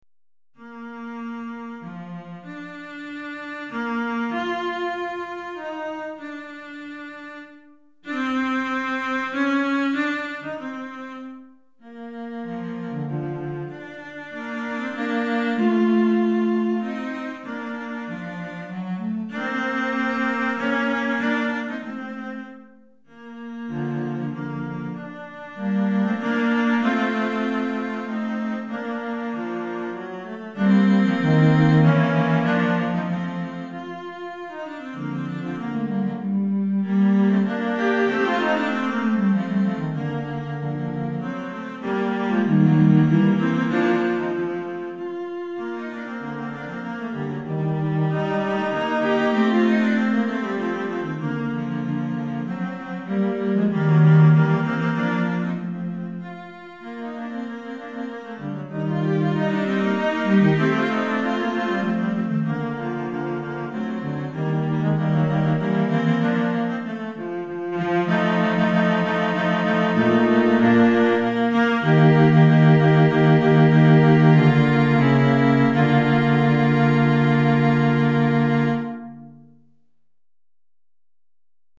3 Violoncelles